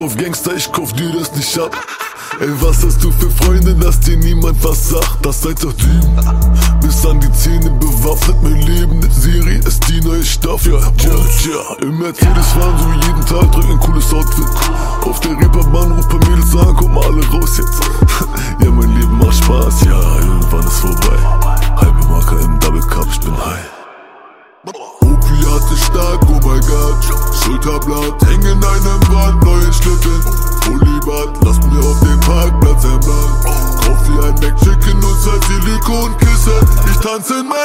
Kategorie Rap/Hip Hop